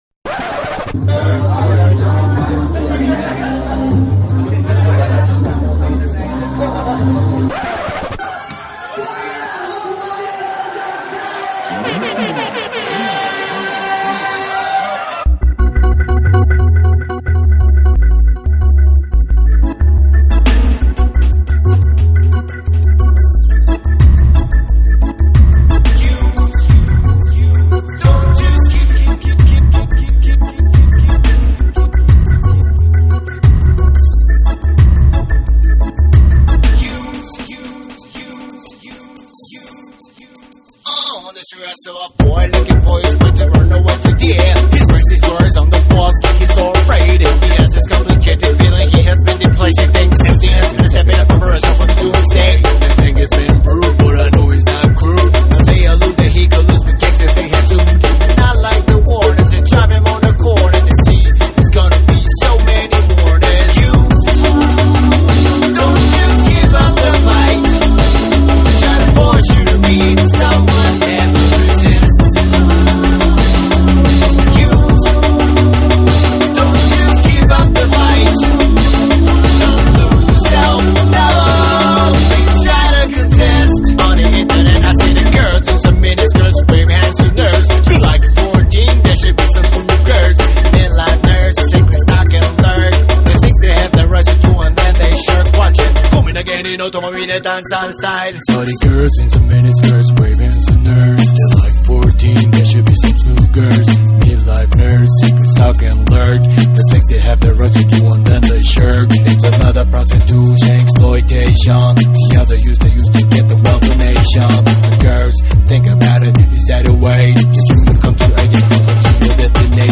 篠笛、琴など和要素を盛り込んだBPM140のオリエンタルなダブステップ
JAPANESE / REGGAE & DUB / JUNGLE & DRUM'N BASS